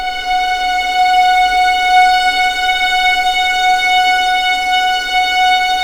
F#5LEGPVLN R.wav